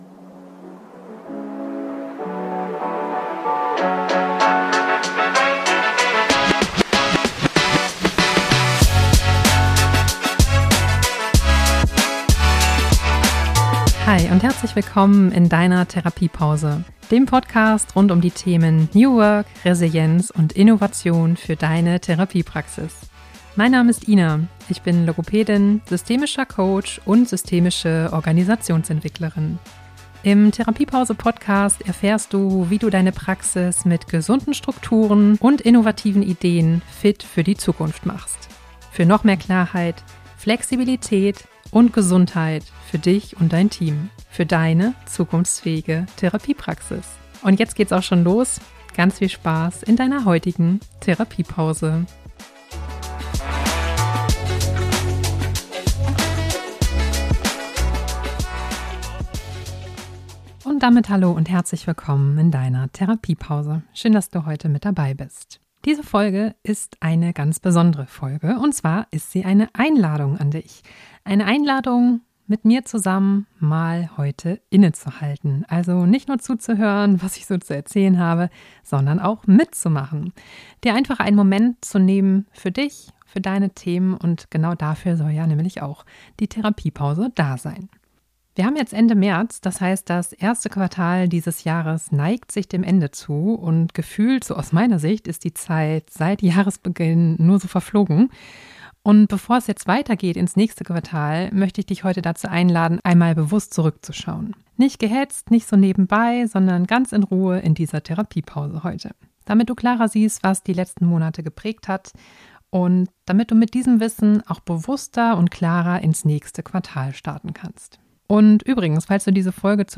In dieser Folge führe ich dich durch eine angeleitete Reflexionsübung, mit der du auf dein letztes Quartal zurückblickst - mit echten Pausen zum Nachdenken. Für mehr Klarheit, Selbstfürsorge und Entlastung im Praxisalltag.